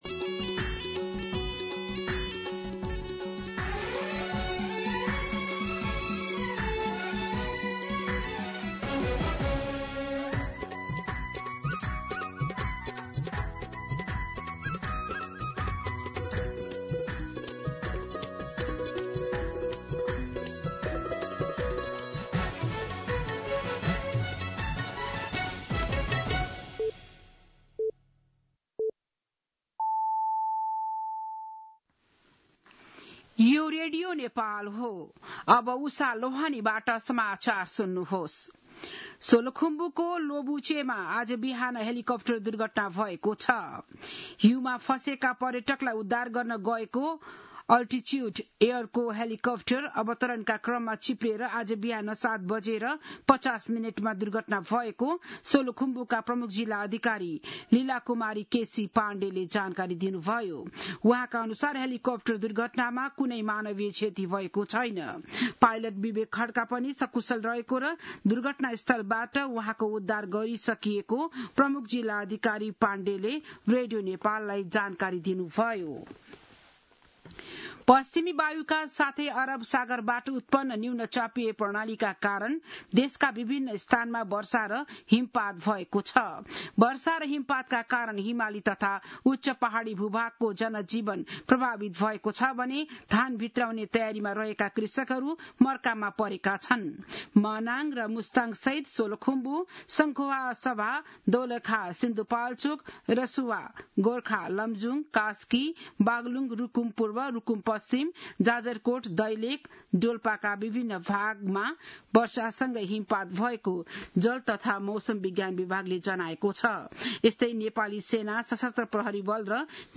बिहान ११ बजेको नेपाली समाचार : १२ कार्तिक , २०८२